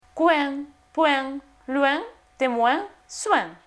oin coin — point — loin — témoin — soin.mp3